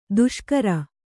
♪ duṣkara